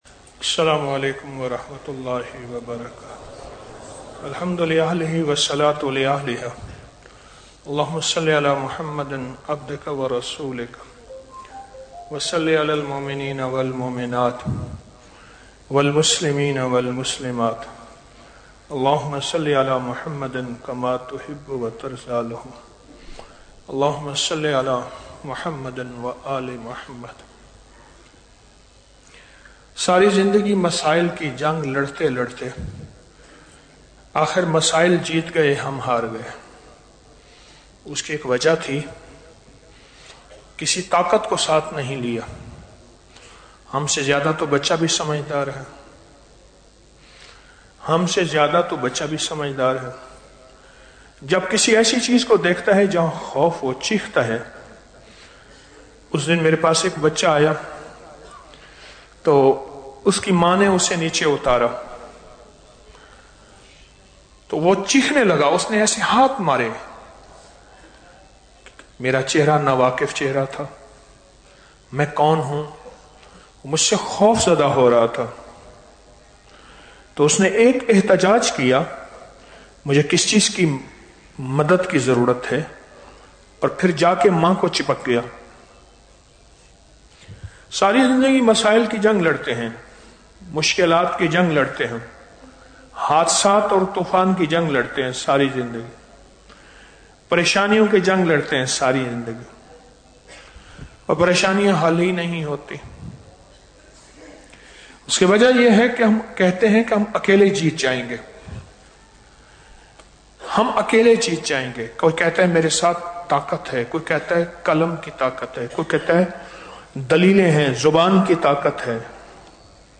شبِ جمعہ محفل